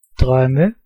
Ääntäminen
Vaihtoehtoiset kirjoitusmuodot (vanhentunut) dreame Synonyymit sweven heaven vision envision lulu Ääntäminen GenAm: IPA : /ˈdɹim/ US : IPA : [dɹim] Tuntematon aksentti: IPA : /ˈdɹiːm/ GenAm: IPA : [d͡ʒɹim]